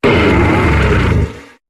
Cri de Tranchodon dans Pokémon HOME.